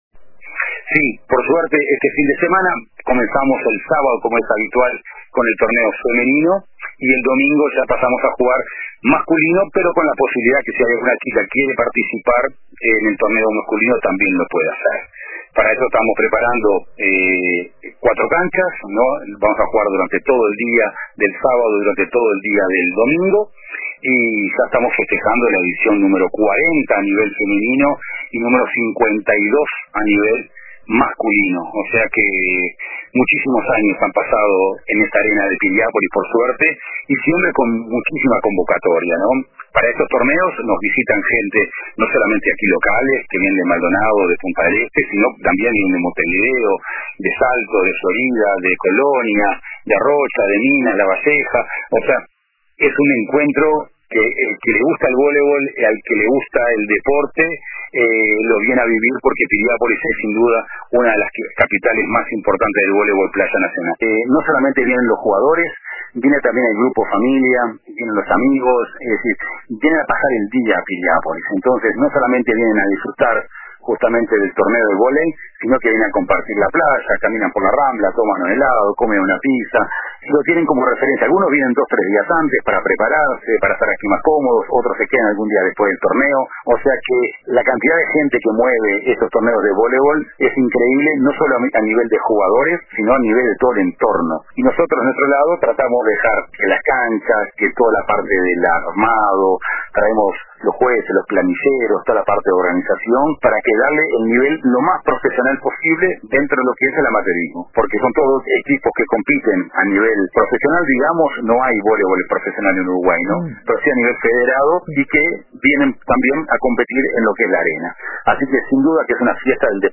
En el programa La Tarde de RBC